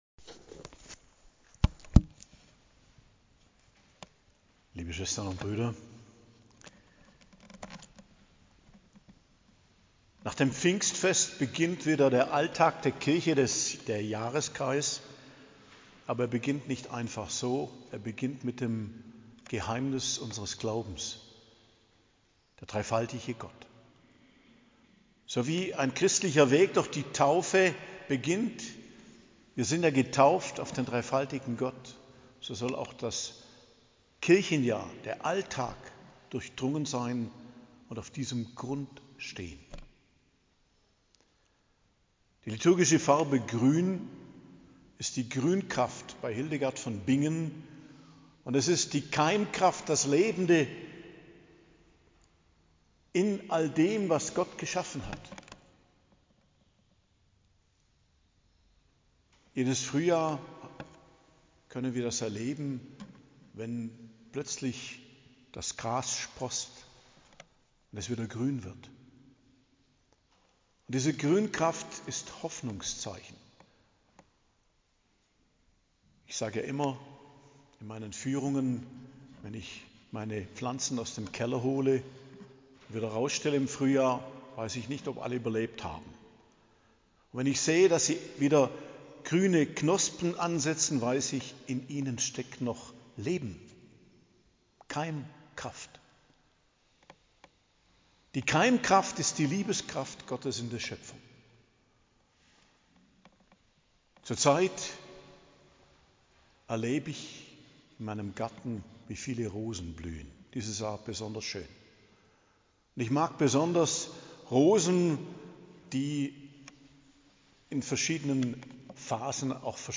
Predigt zum Dreifaltigkeitssonntag, 14.06.2025 ~ Geistliches Zentrum Kloster Heiligkreuztal Podcast